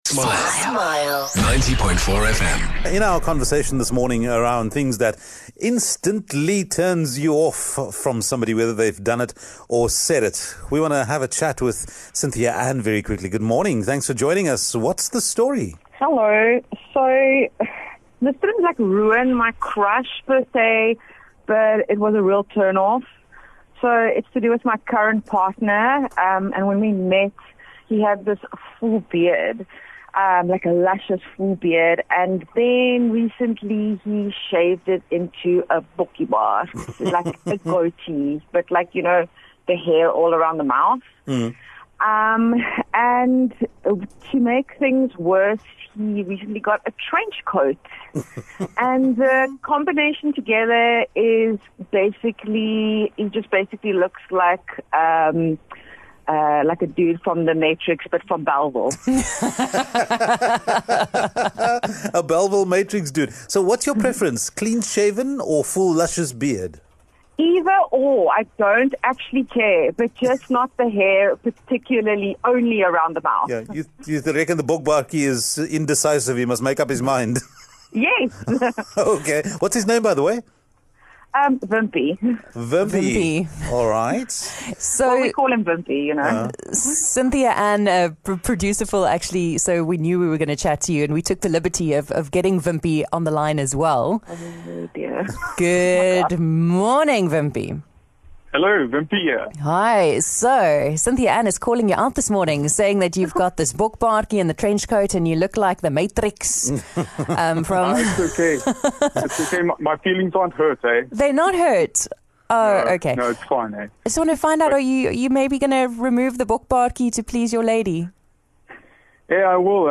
So we thought it would be a bit more fun if we got him on the phone too. He had some complaints of his own.